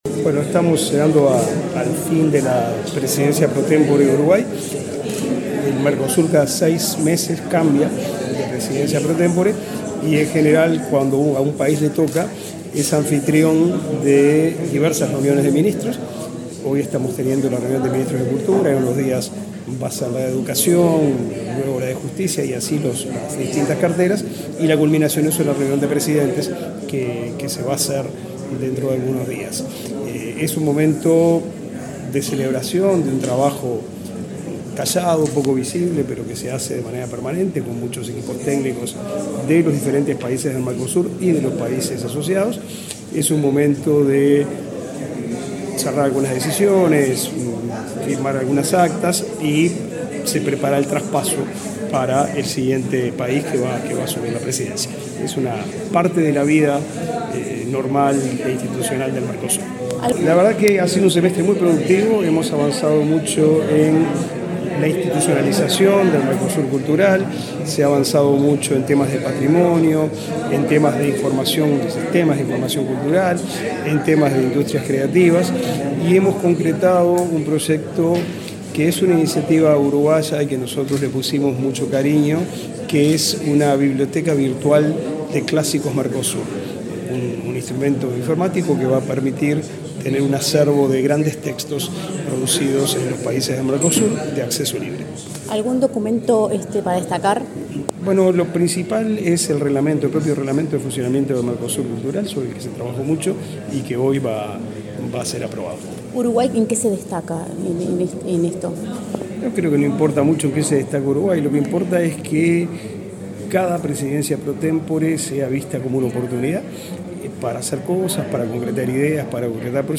Entrevista al ministro de Educación y Cultura, Pablo da Silveira
Entrevista al ministro de Educación y Cultura, Pablo da Silveira 11/11/2022 Compartir Facebook X Copiar enlace WhatsApp LinkedIn El ministro de Educación y Cultura, Pablo da Silveira, y la directora nacional de Cultura, Mariana Wanstein, fueron los anfitriones de la Reunión de Ministros de Cultura del Mercosur, realizada este viernes 11 en Montevideo. Antes el secretario de Estado dialogó con Comunicación Presidencial.